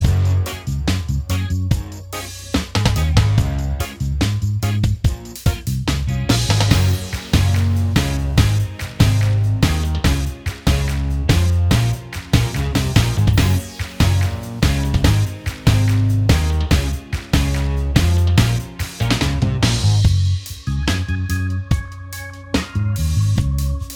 Minus All Guitars Pop (2010s) 3:45 Buy £1.50